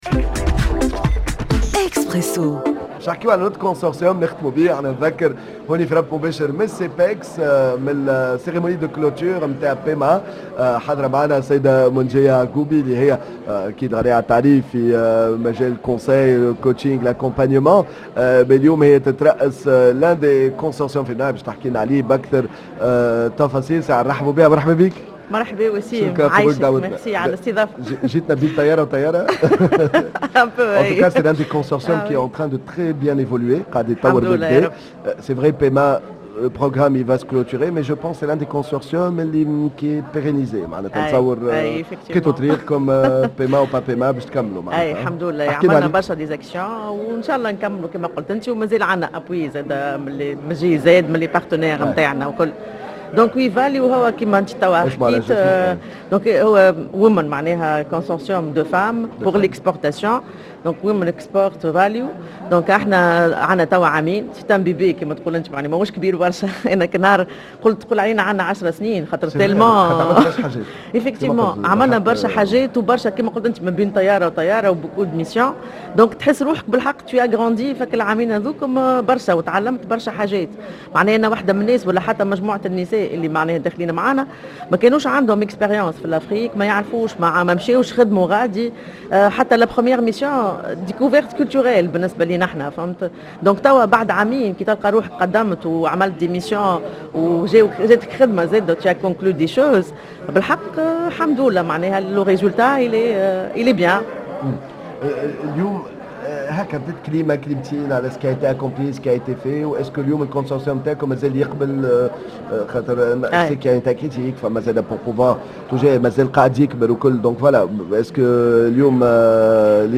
dans un plateau spécial en direct du Tunisia Export – Centre de Promotion des Exportations CEPEX